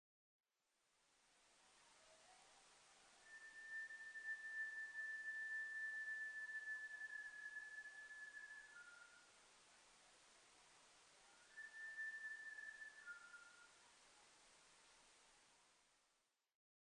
ニホンジカ　Cervus nipponシカ科